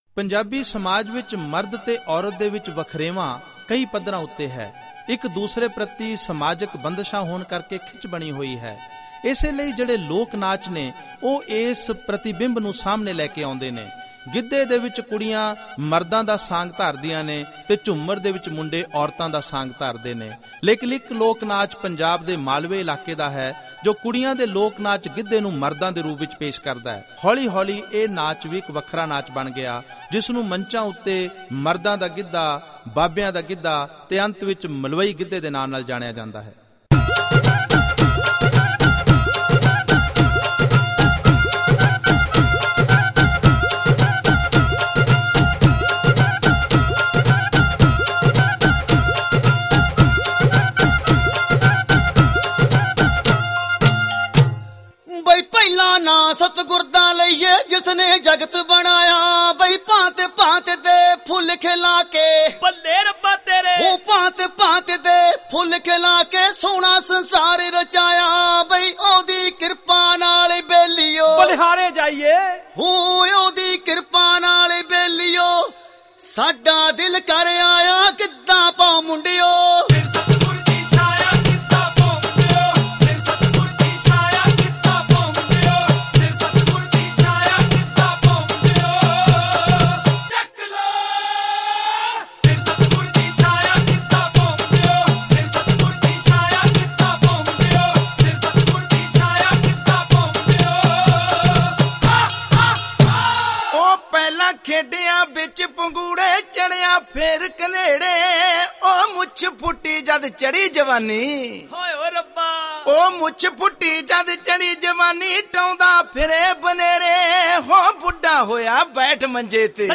Punjabi Bhangra MP3 Songs
Only Folk